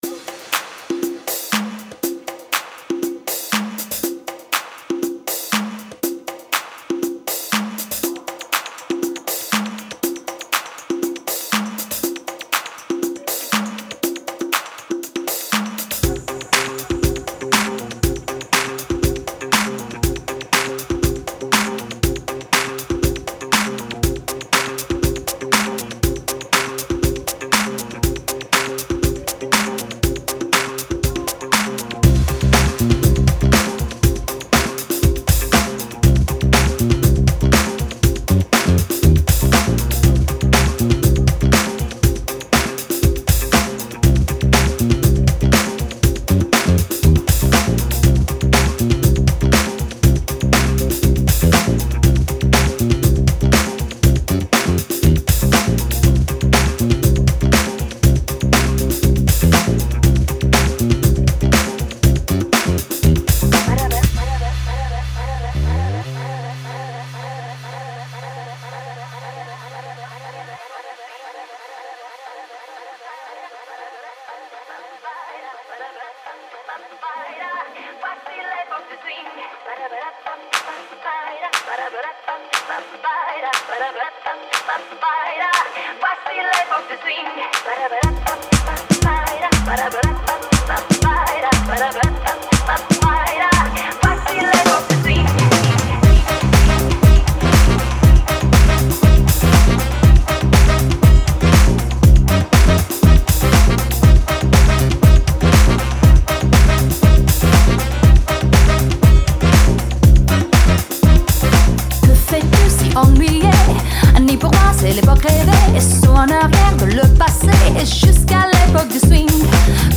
Когда-то делал данный ремикс
из оригинала тут только вокал и пачка духовых, но не сакс